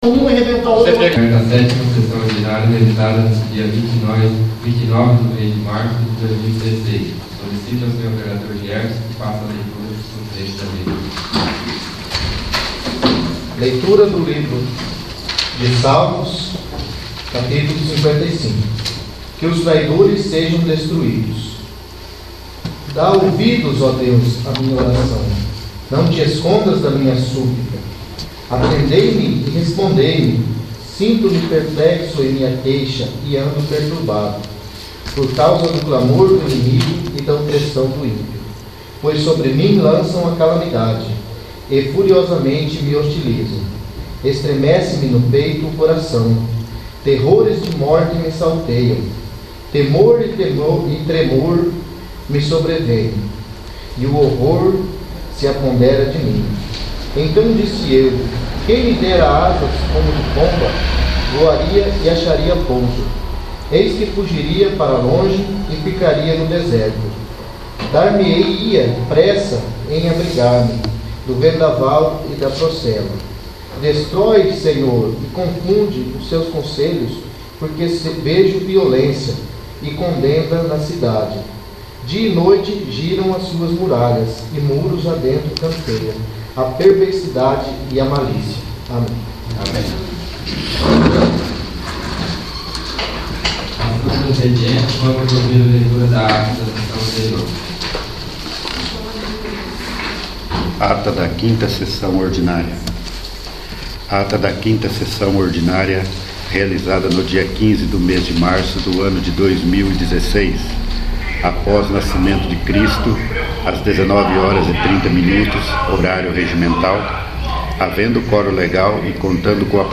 6º. Sessão Ordinária